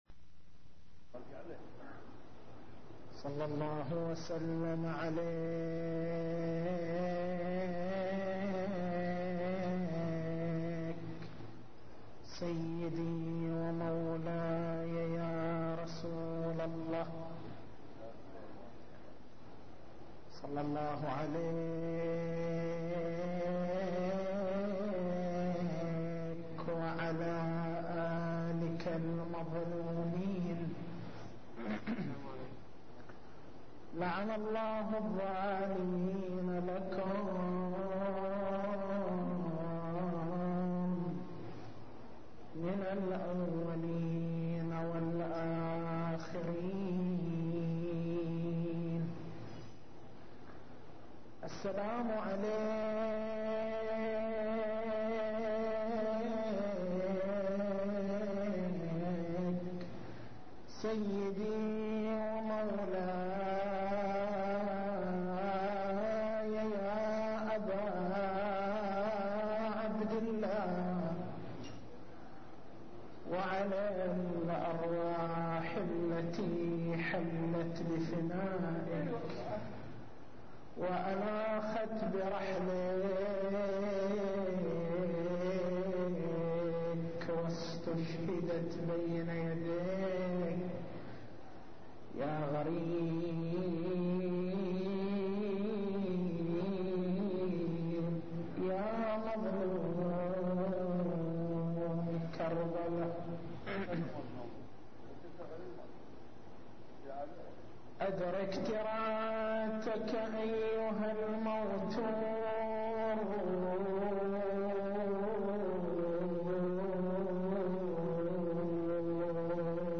تاريخ المحاضرة: 01/01/1423 نقاط البحث: معنى الأمر أهمية إحياء الأمر أساليب إحياء الأمر التسجيل الصوتي: تحميل التسجيل الصوتي: شبكة الضياء > مكتبة المحاضرات > محرم الحرام > محرم الحرام 1423